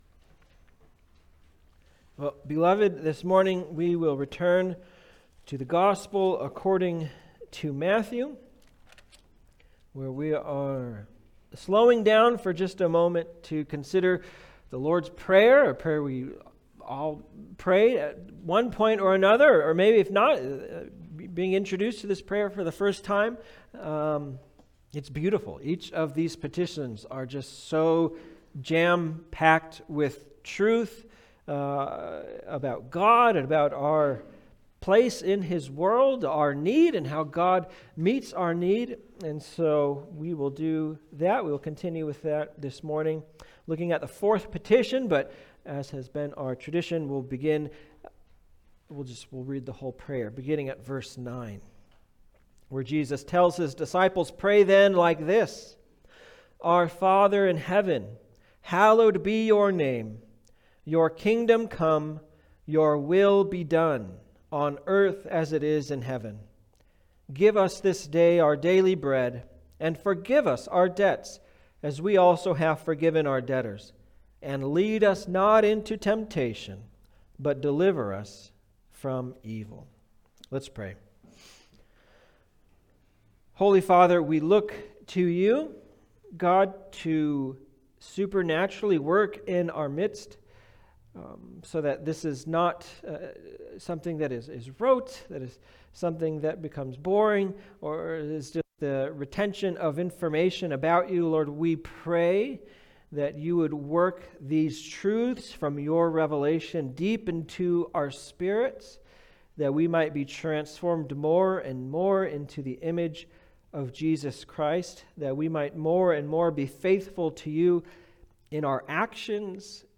Passage: Matthew 6:11 Service Type: Sunday Service